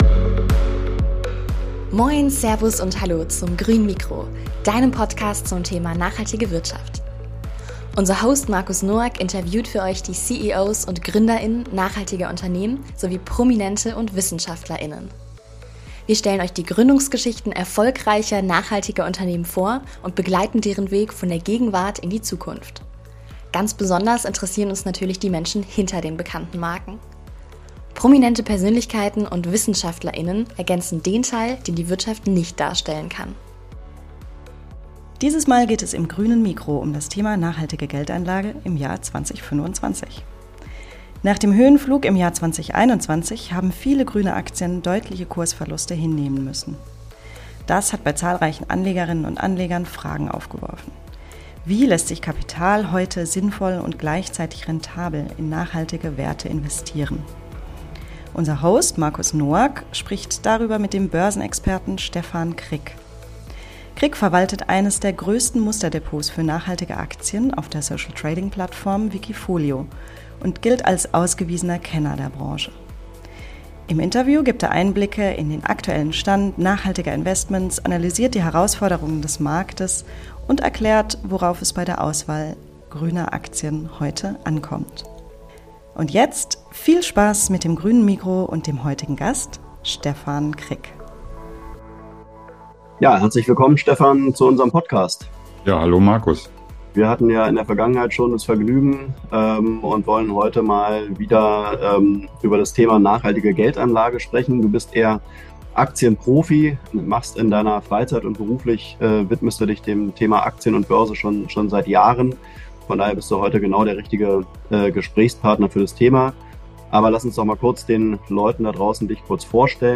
Im Interview gibt er Einblicke in den aktuellen Stand nachhaltiger Investments, analysiert die Herausforderungen des Marktes und erklärt, worauf es bei der Auswahl grüner Aktien heute ankommt.